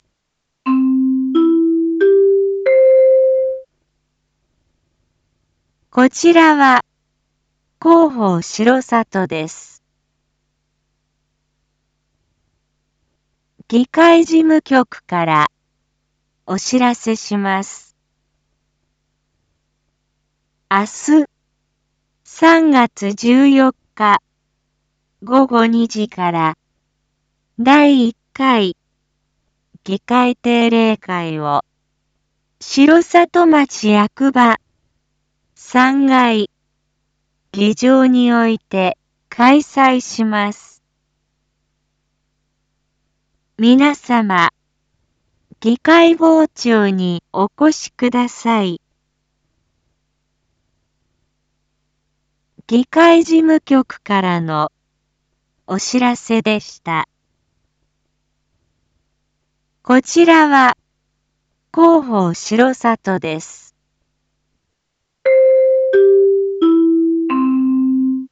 一般放送情報
Back Home 一般放送情報 音声放送 再生 一般放送情報 登録日時：2025-03-13 19:01:09 タイトル：議会定例会（７） インフォメーション：こちらは広報しろさとです。